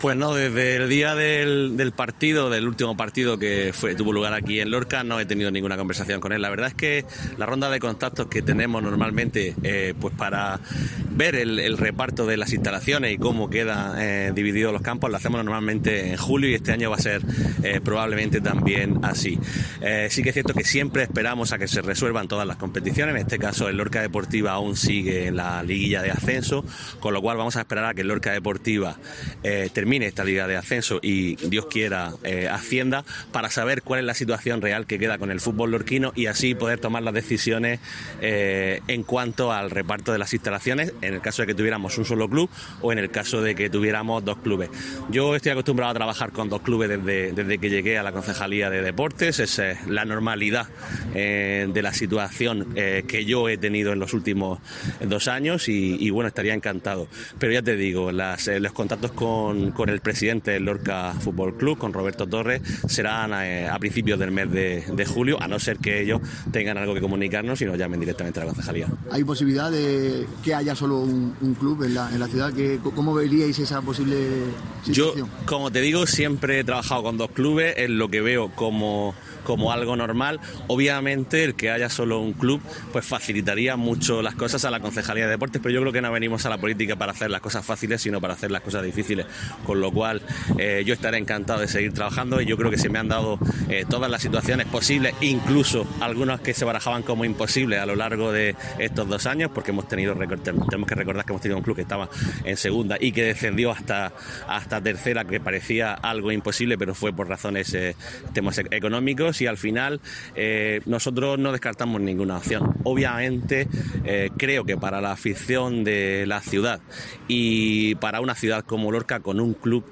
Juan Miguel Bayonas, edil en funciones de Deportes sobre Lorca FC